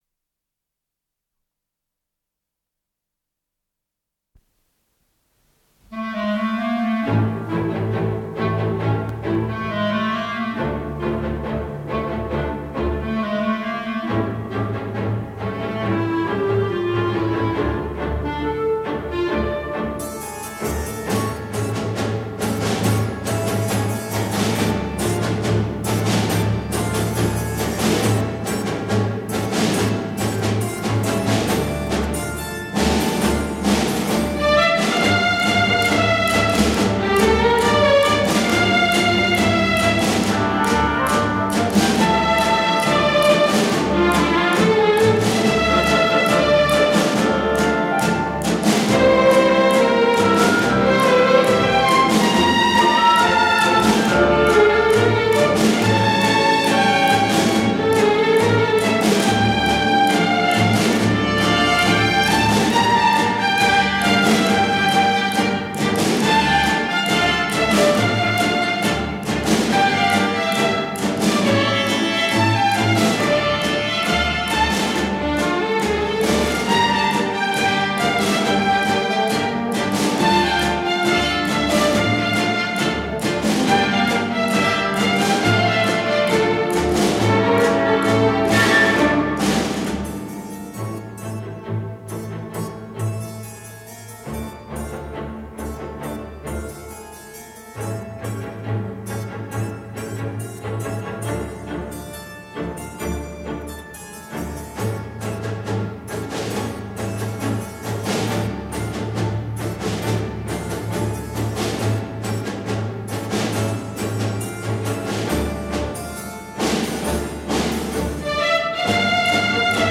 Стерео дубль.